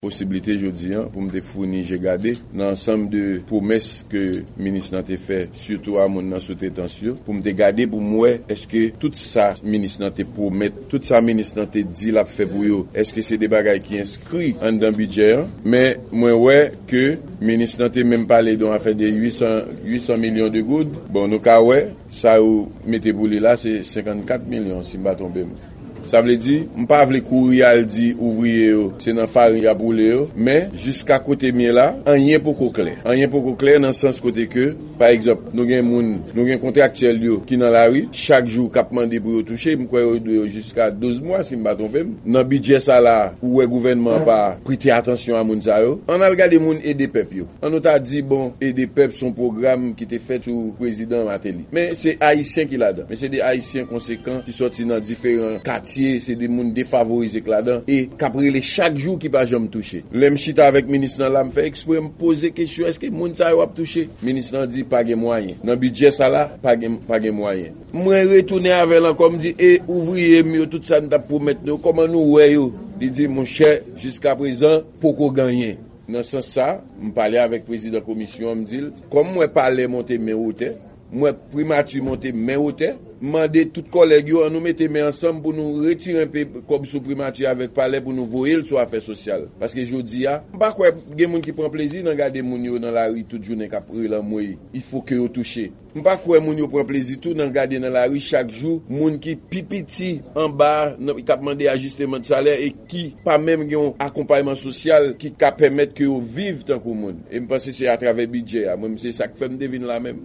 Deklarasyon Senatè Antonio Cheramy